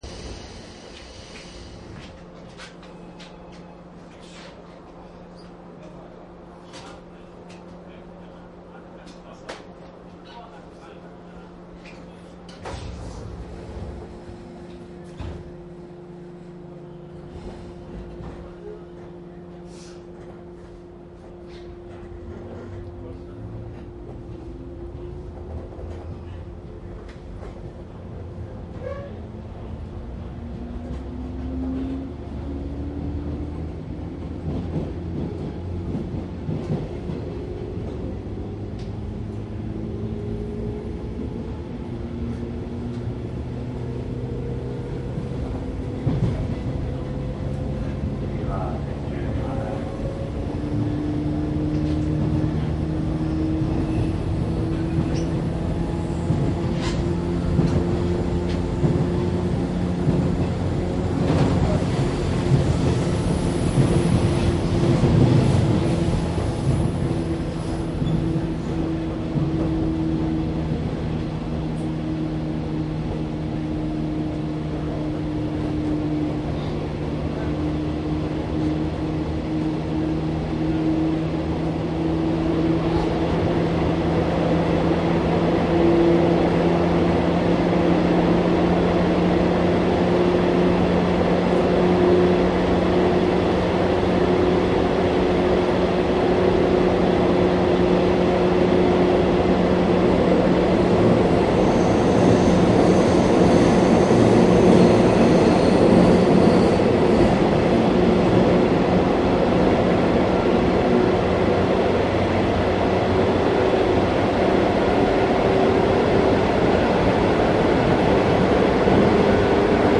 JR京葉線 103系 走行音
内容は♪JR京葉線 103系走行音 ＞上り＜で103系を録音していますがアナウンスの音量が小さいです。
■【各駅停車】蘇我→東京 モハ103－211（初期型主電動機）
マスター音源はデジタル44.1kHz16ビット（マイクＥＣＭ959）で、これを編集ソフトでＣＤに焼いたものです。